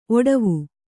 ♪ oḍavu